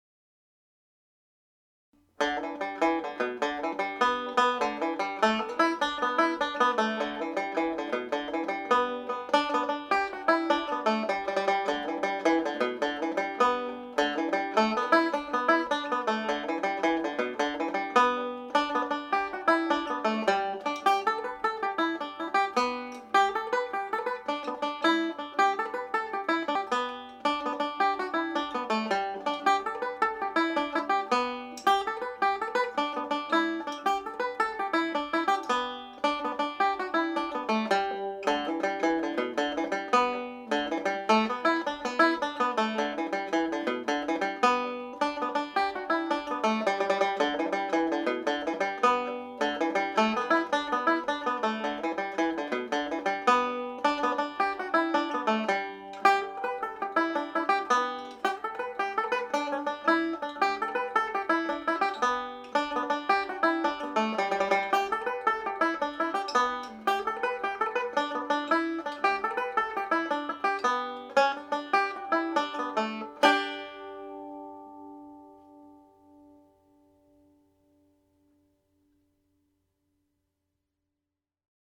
The Butlers of Glen Avenue played jig speed